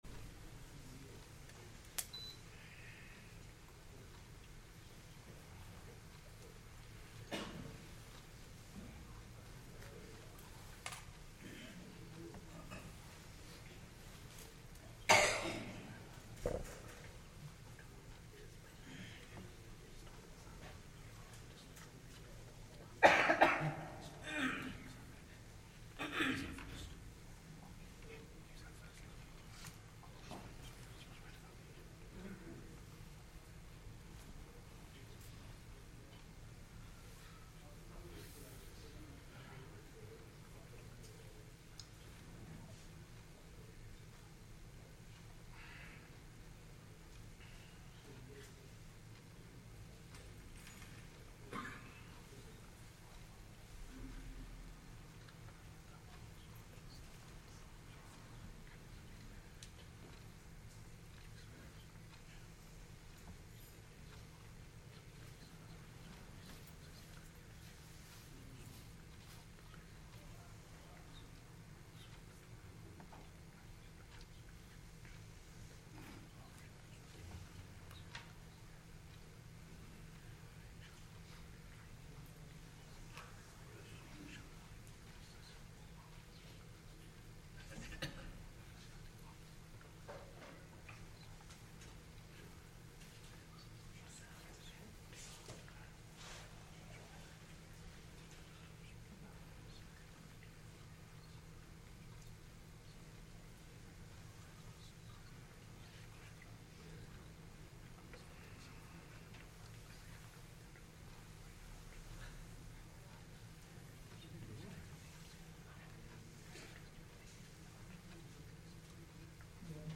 Jumuah